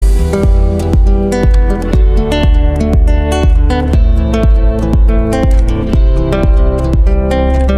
Kategorien Klassische